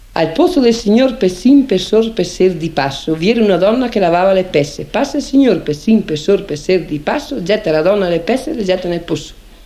13 al posso del signor pessin (scioglilingua).mp3